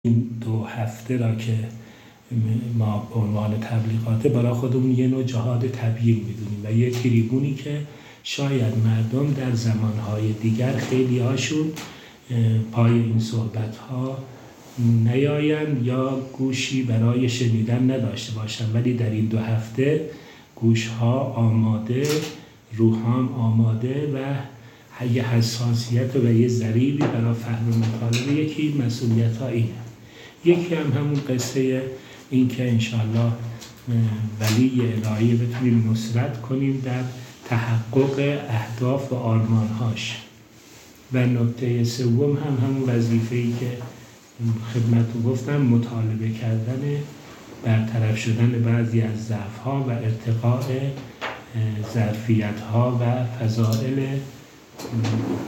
در گفت‌وگو با ایکنا از چهارمحال‌وبختیاری